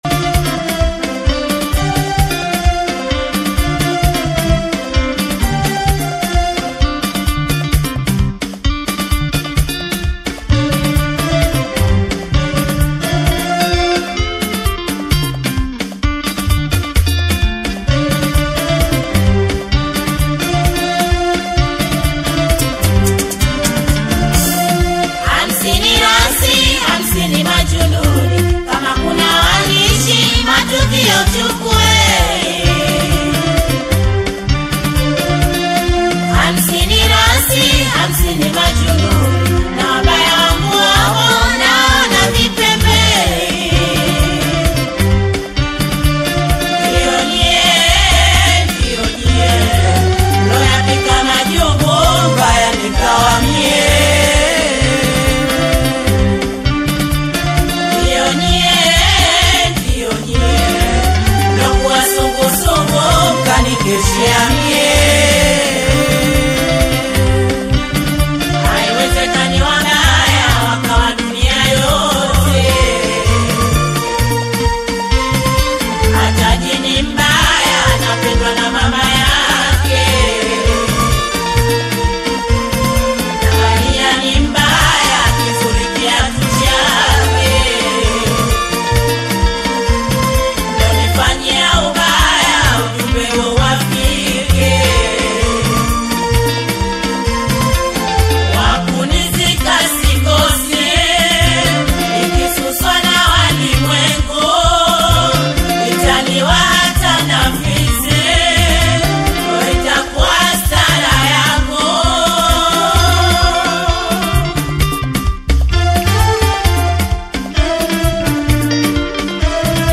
vibrant and energetic
blending catchy beats with clever lyrics.